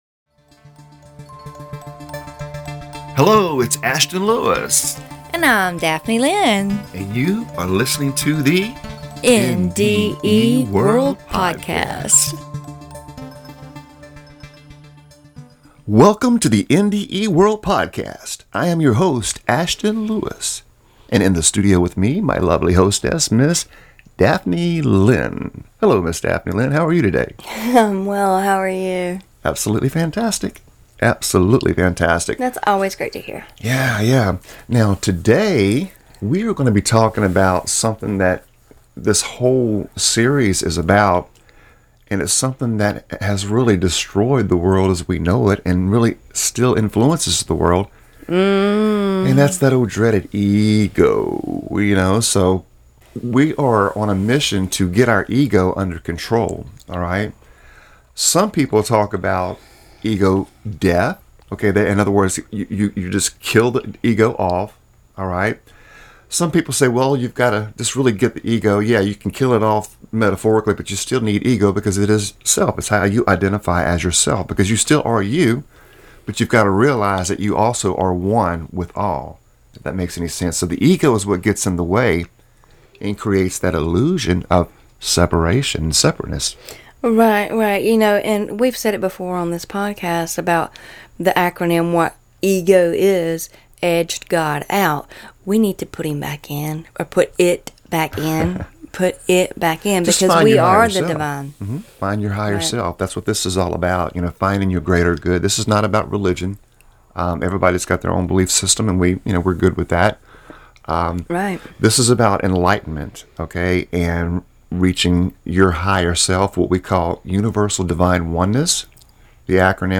In this series, we will share the world of UDO pronounced (yoo' dō), with our listeners. UDO is the acronym for Universal Divine Oneness, and we will help you to reach that state of UDO.